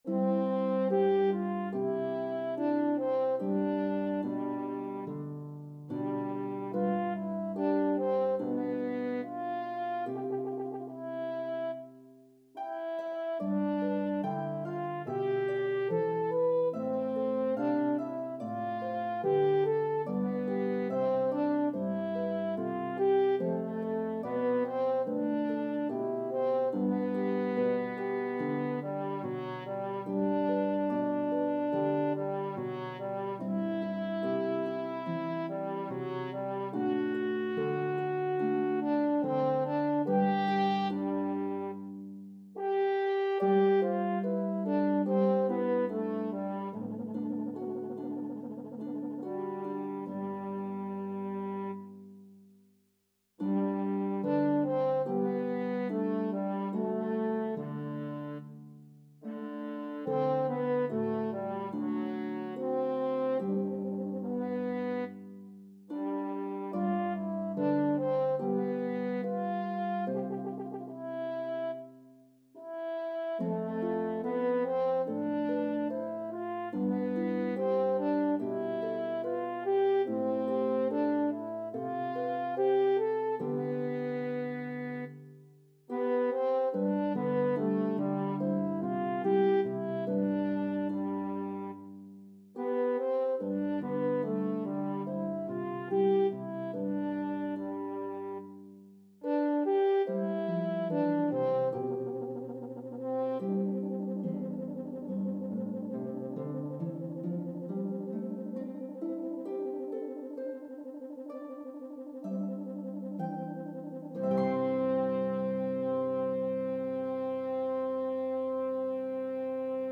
with Lever Harp accompaniment is a delight to play and hear.